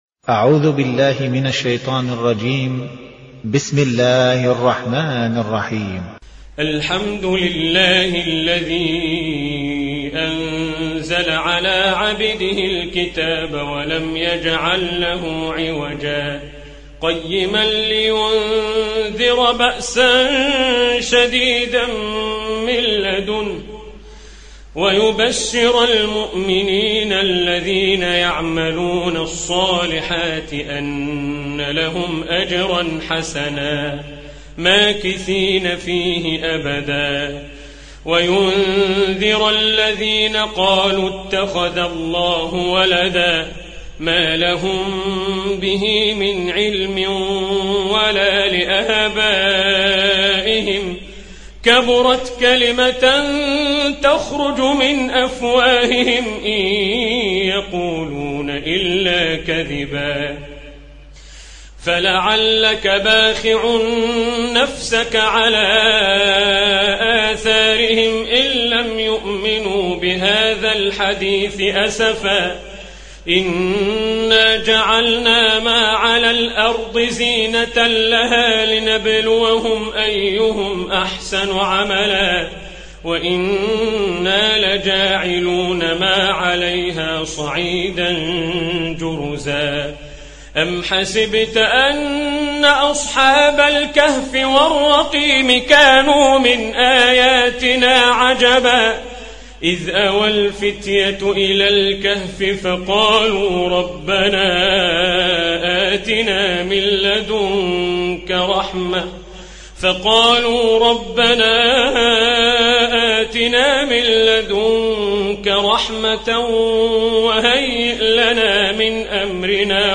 (رواية حفص)